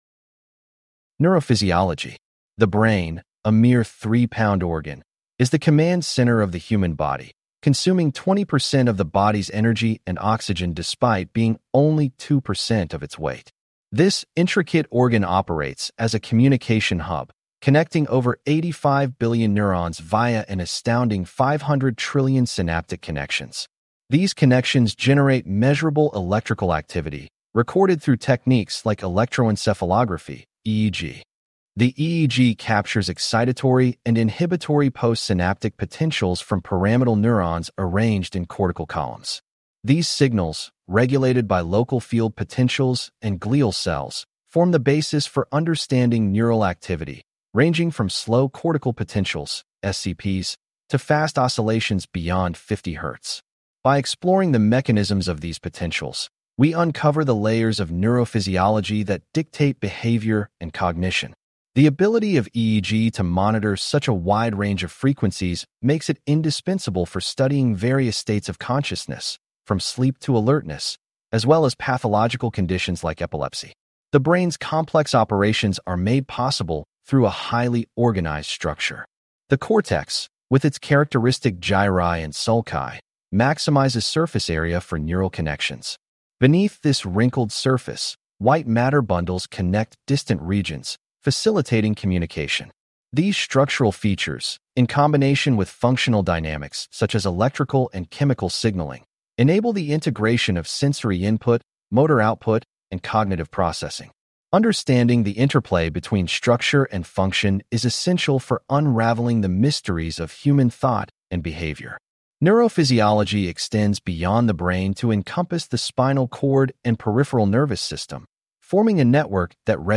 This unit covers the Bioelectric Origin and Functional Correlates of EEG, Definition of ERPs and SCPs, and Neuroplasticity. Please click on the podcast icon below to hear a full-length lecture.